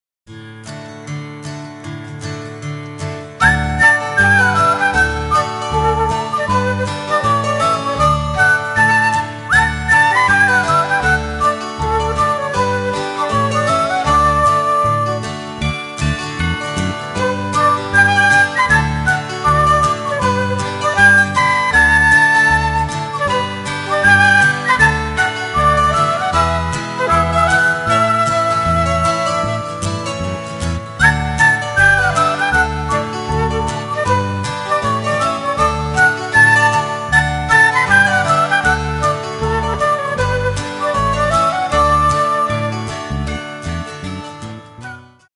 --funny kids' music